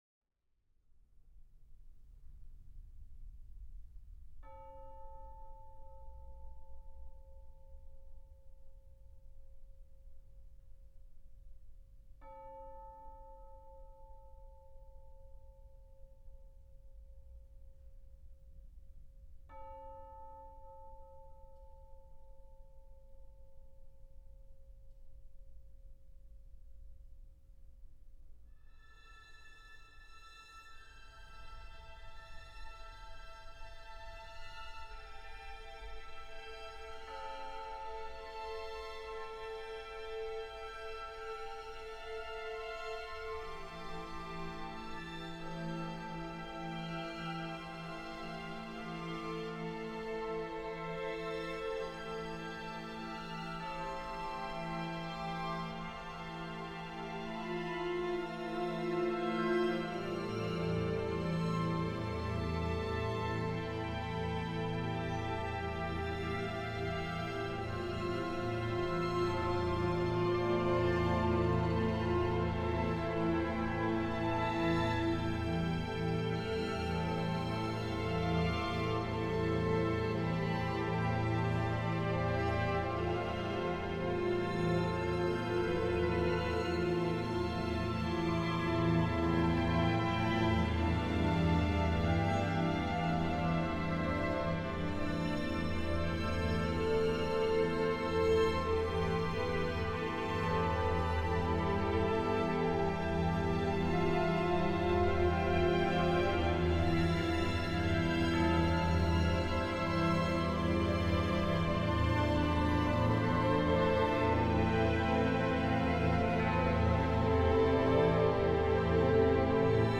for string orchestra and bell